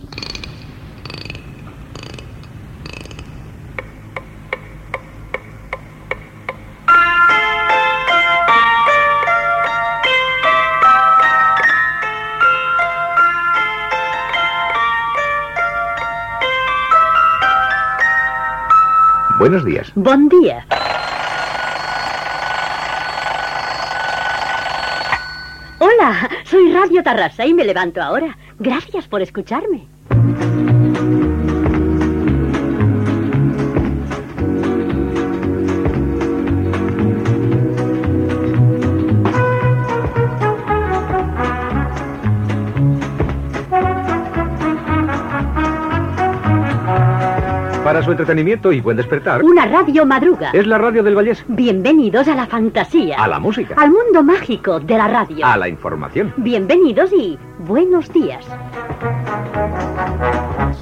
Careta del programa.
Entreteniment